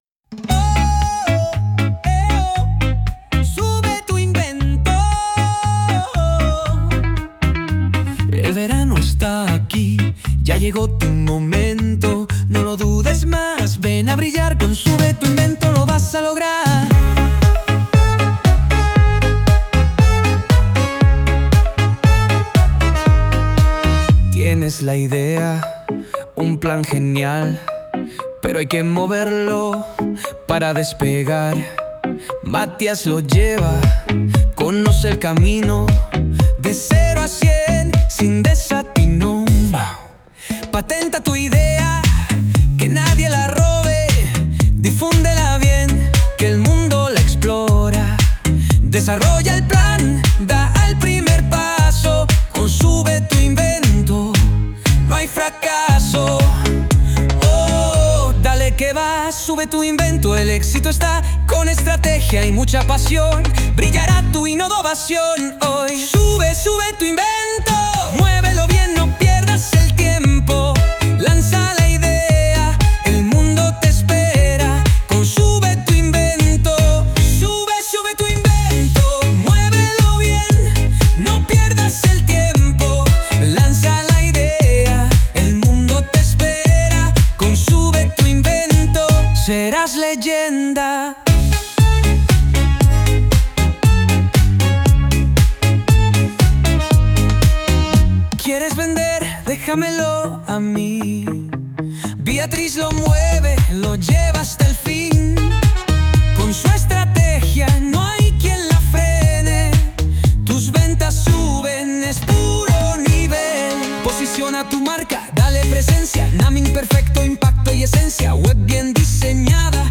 Sube-Tu-Invento-Reggae.mp3